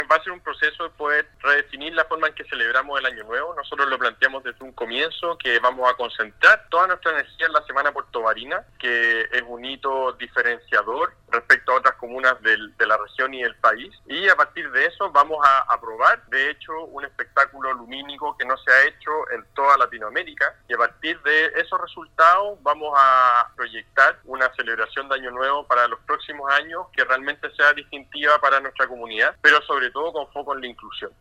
En conversación con Radio Sago el alcalde de Puerto Varas dijo asumir la inquietud y preocupación del gremio del turismo, tras el balance negativo que realizó tras la suspensión del show pirotécnico de Año Nuevo y la notable baja de turistas durante las fiestas de fin de año. El edil señaló que ello se transforma en una oportunidad para redefinir cómo se celebra el año nuevo en la comuna lacustre y permitirá concentrar los esfuerzos en la celebración de la semana puertovarina.